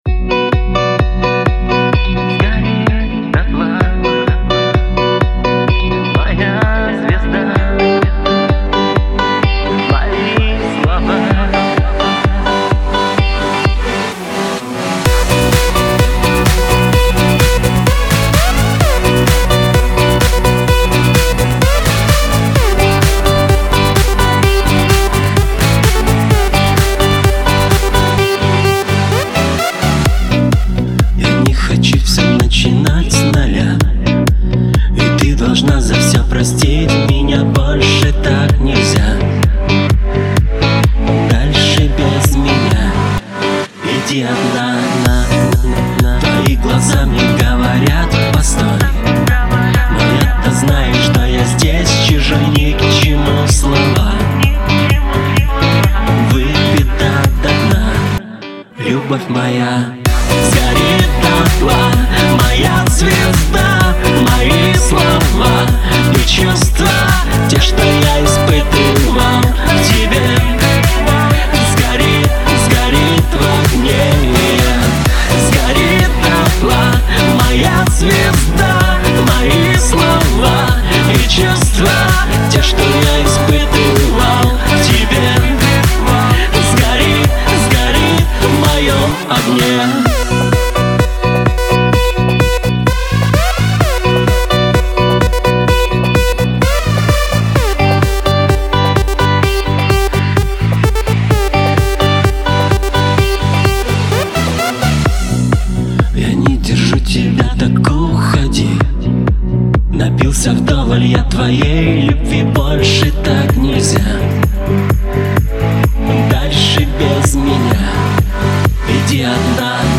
pop
Лирика